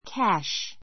cash A2 kǽʃ キャ シュ 名詞 現金 pay (in) cash pay (in) cash 現金で払 はら う ⦣ pay cash の cash は「現金で」という意味でin cash と同じ.